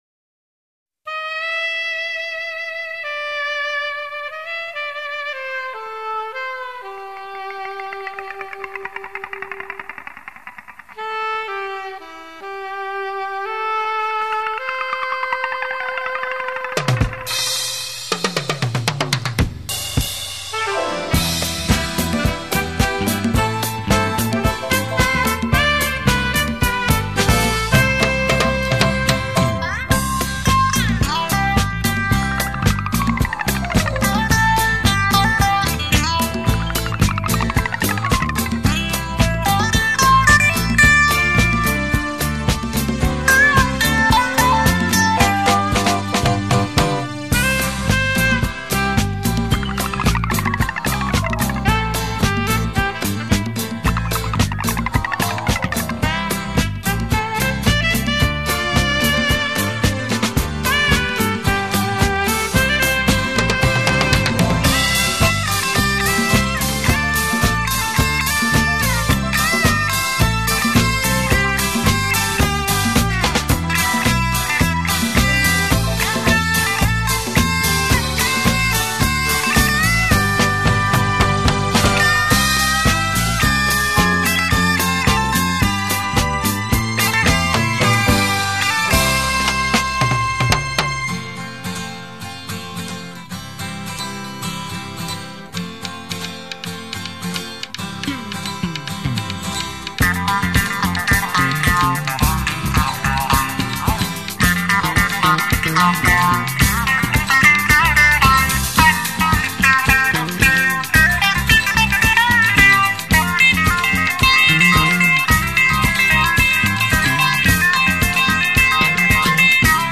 吉他主演乐队加盟，再奏名歌旋律新曲。